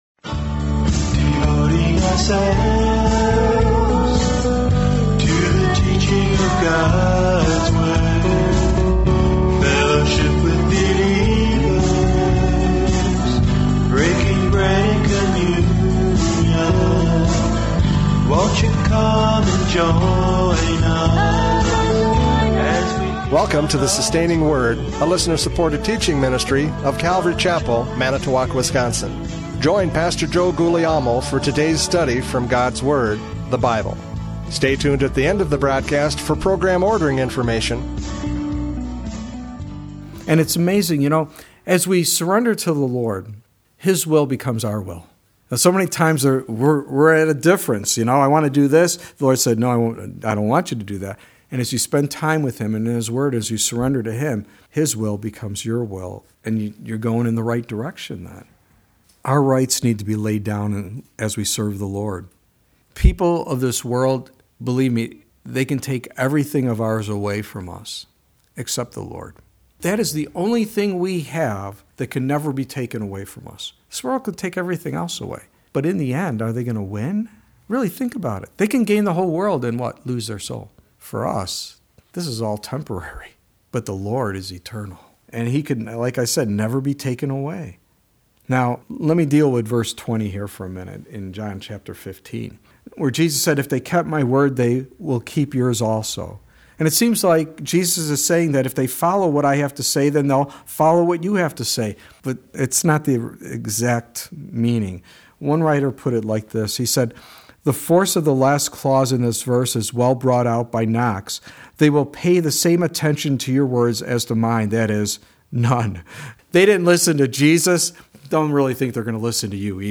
John 15:18-27 Service Type: Radio Programs « John 15:18-27 The World’s Response!